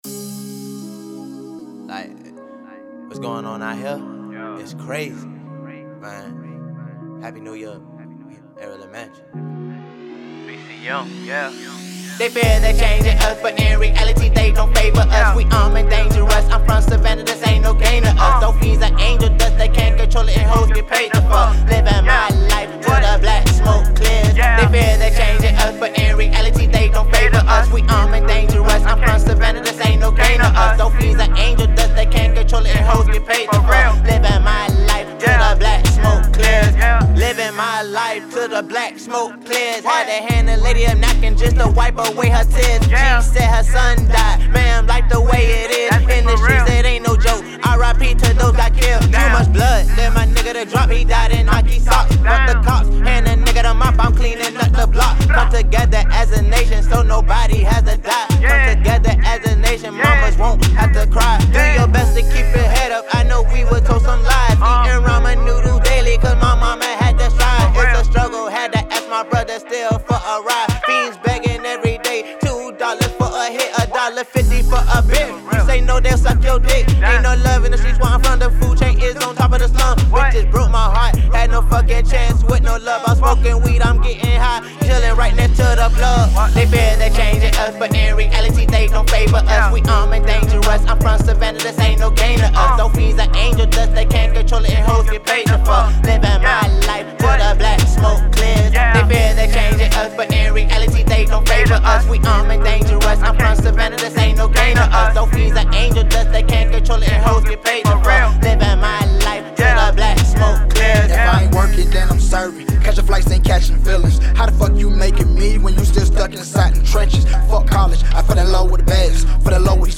combining art with New Age Rap & POP music